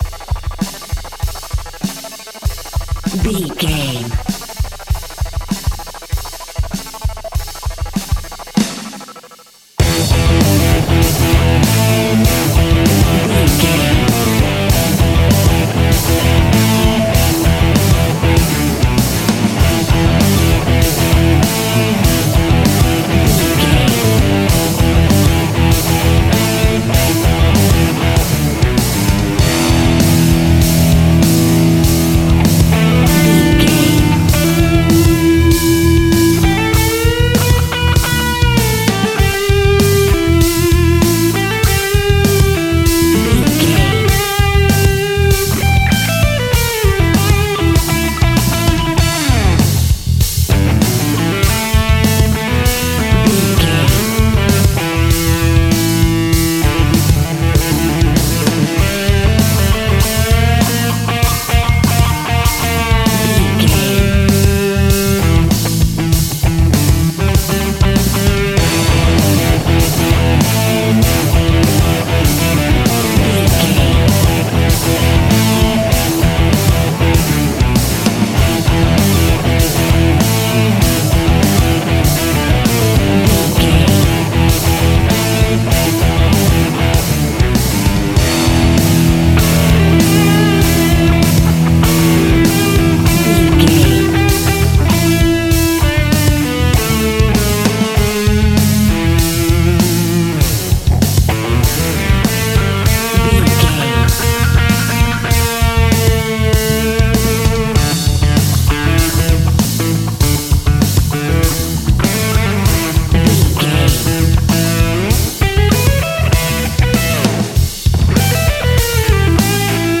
Ionian/Major
energetic
driving
heavy
aggressive
electric guitar
bass guitar
drums
hard rock
heavy metal
blues rock
distortion
heavy drums
distorted guitars
hammond organ